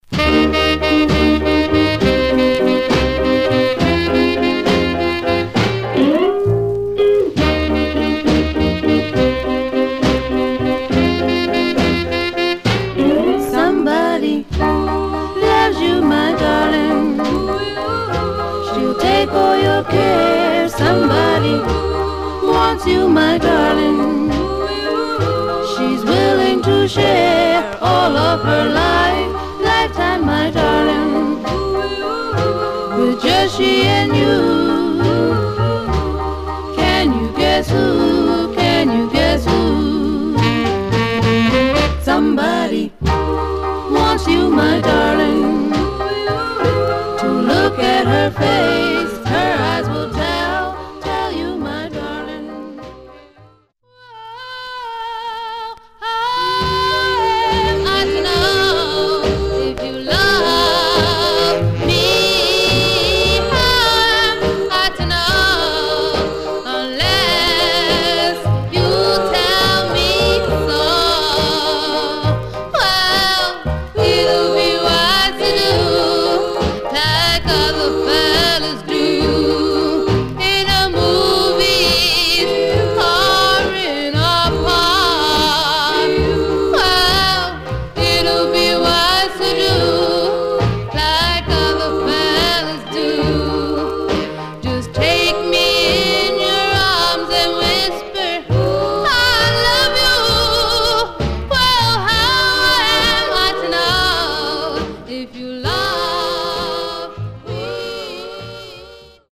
Some surface noise/wear
Mono
Black Female Group